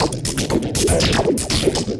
Index of /musicradar/rhythmic-inspiration-samples/120bpm